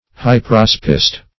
Search Result for " hyperaspist" : The Collaborative International Dictionary of English v.0.48: Hyperaspist \Hy`per*as"pist\, n. [Gr.